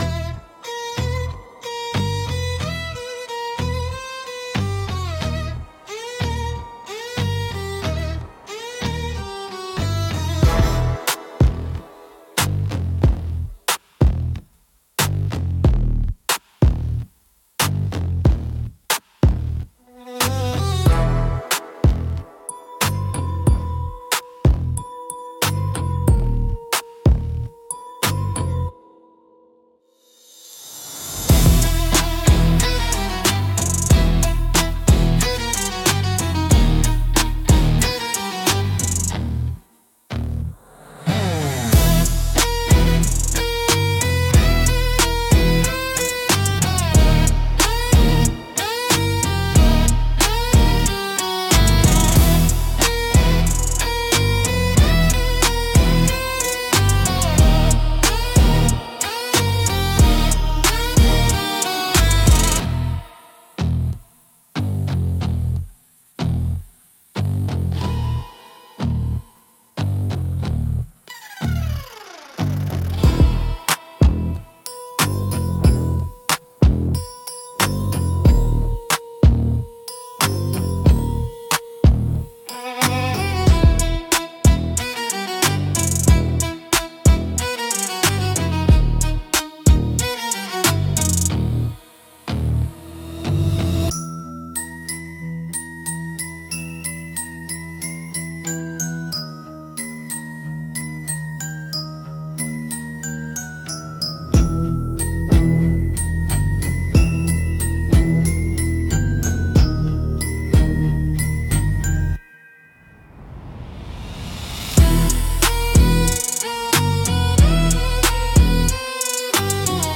Instrumental - The Look That Burns - 2.39